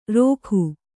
♪ rōkhi